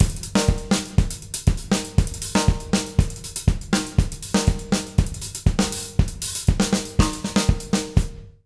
120ZOUK 05-L.wav